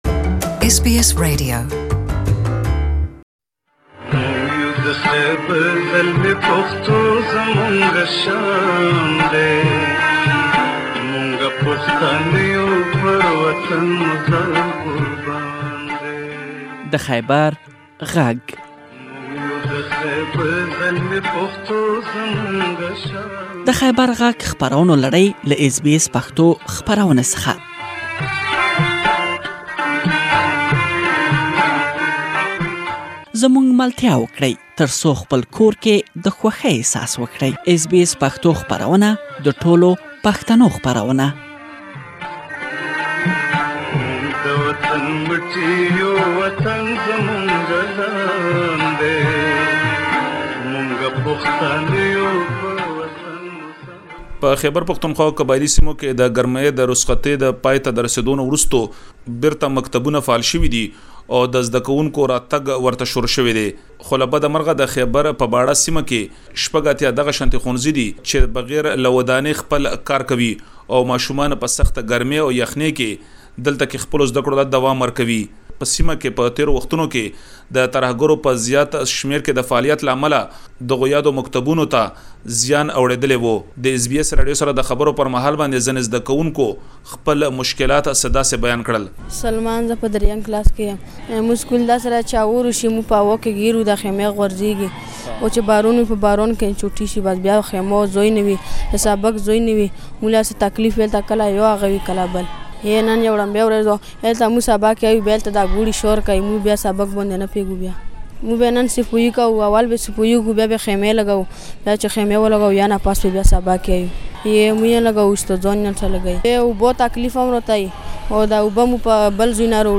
پدې اړه راپور برابر کړی.